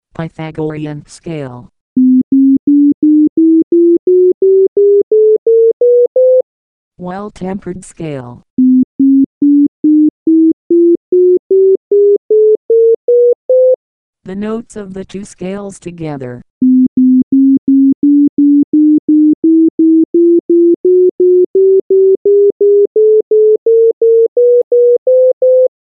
Consider the Pythagorean Scale, for which the frequency ratios to the lower C note are shown above. In the Well-Tempered Scale, on the other hand, the ratio of each note to the one preceding it is a constant 21/12.
Scales.mp3